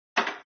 drop.mp3